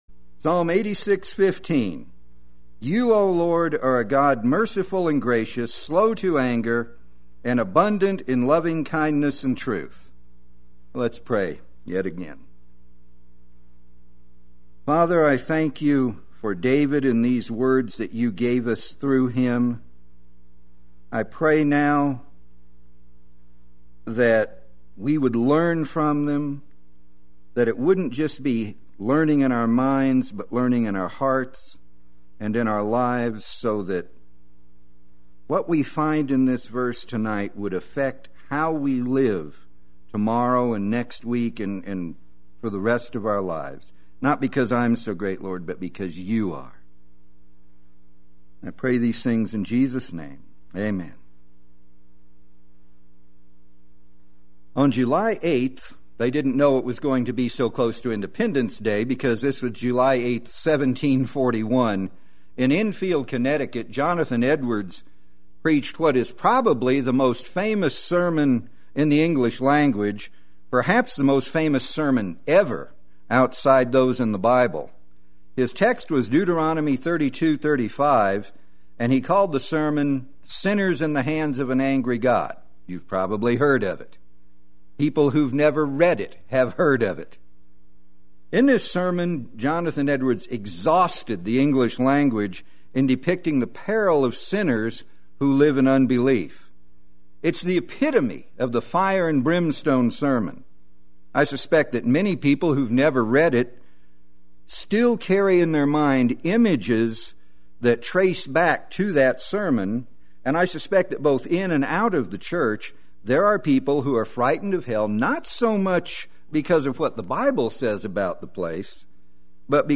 Play Sermon Get HCF Teaching Automatically.
Sinners in the Hand of a Merciful God Wednesday Worship